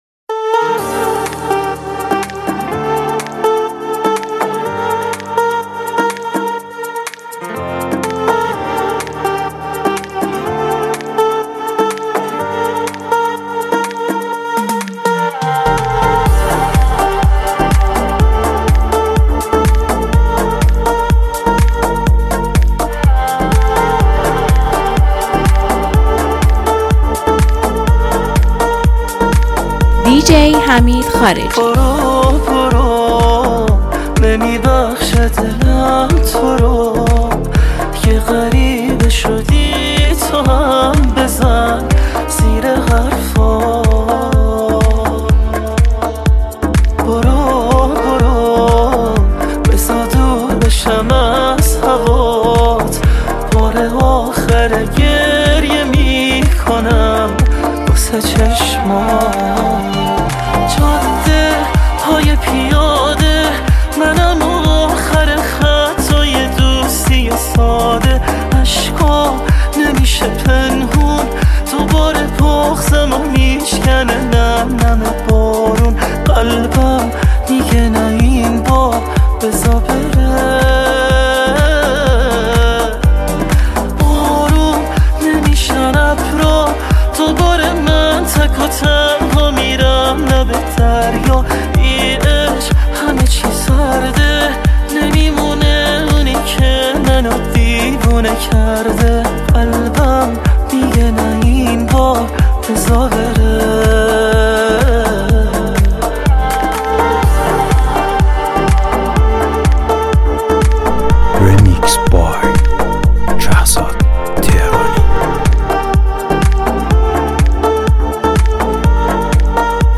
مخصوص مهمانی، دورهمی و لحظات پرانرژی شما.